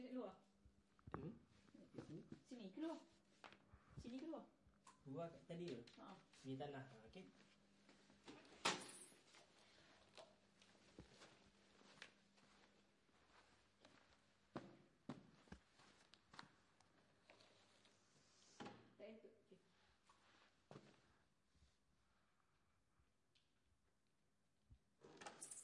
脚步声地毯（泥土）2
描述：脚步声地毯污垢声音环境自然周围的现场录音 环境foley录音和实验声音设计。
Tag: 地毯 脚步声 声音 周围 环境 污垢 现场记录 自然